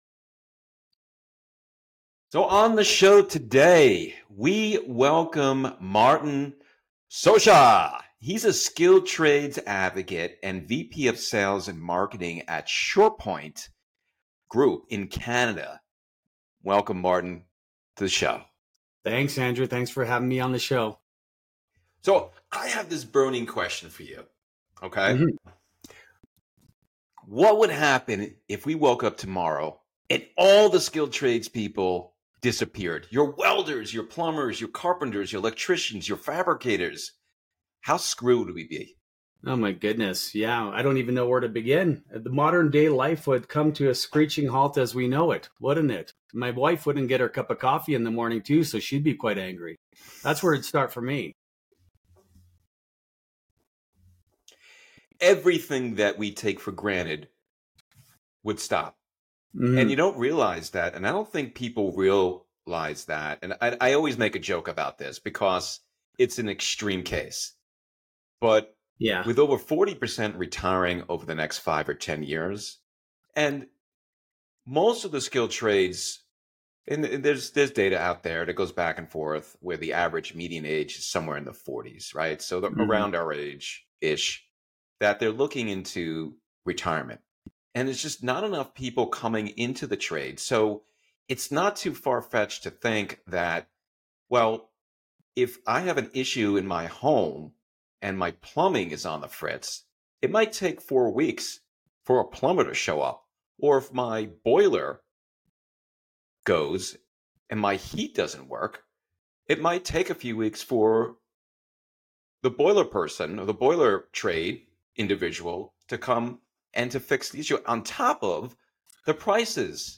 Our guests touch upon the pivotal role that financial education and mentorship play in grooming the tradesmen and women of tomorrow, urging the older generation to shift from criticism to engagement.